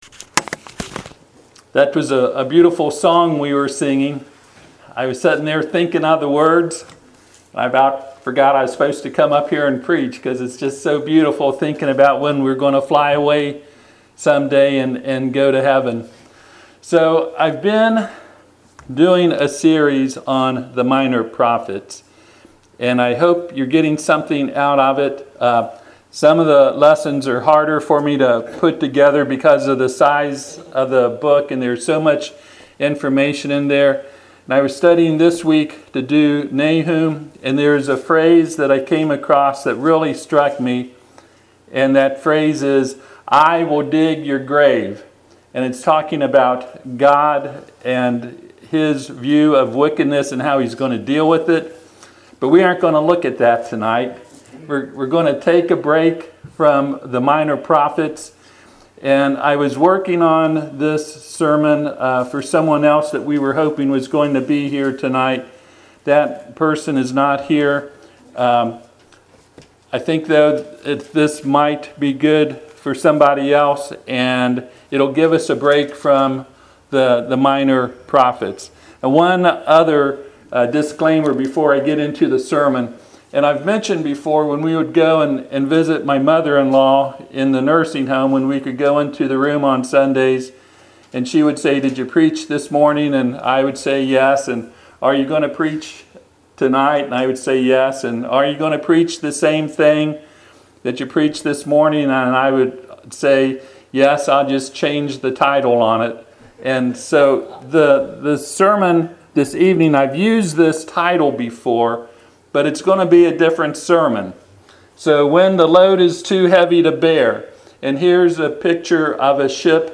Passage: 2 Corinthians 1:8-11 Service Type: Sunday PM « Can We Be Saved Without Repenting?